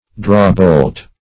drawbolt - definition of drawbolt - synonyms, pronunciation, spelling from Free Dictionary Search Result for " drawbolt" : The Collaborative International Dictionary of English v.0.48: Drawbolt \Draw"bolt`\, n. (Engin.) A coupling pin.